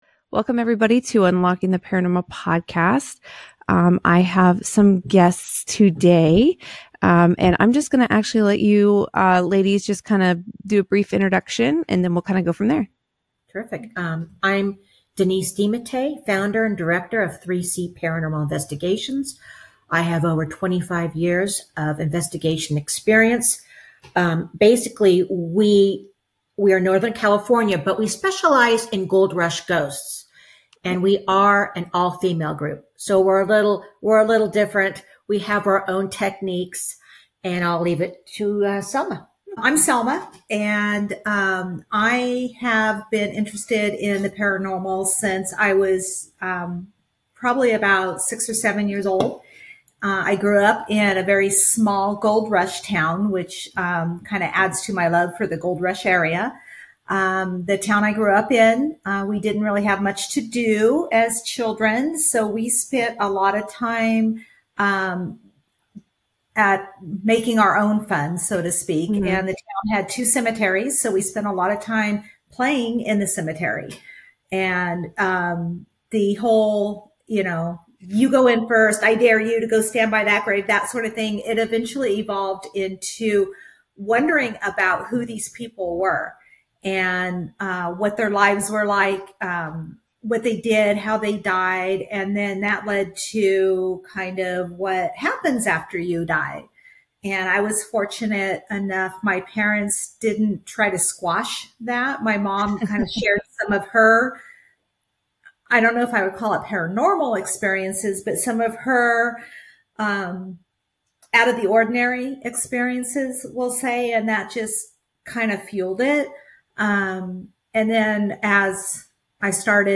Interview with 3-C Paranormal Investigations
In this spine-tingling episode, I sit down with the incredible team from 3-C Paranormal Investigations, a group of passionate investigators who chase the unexplained — and often find it.